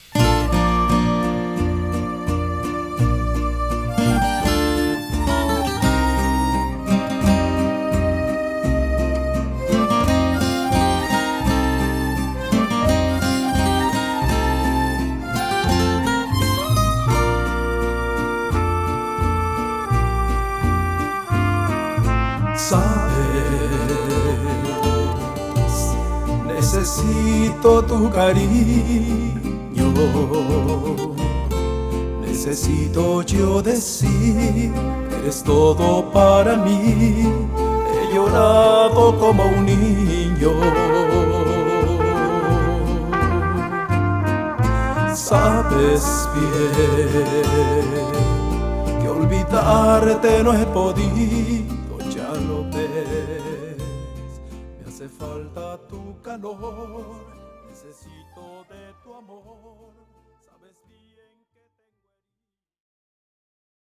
Here are some tracks from the studio that we hope you enjoy.
Mariachi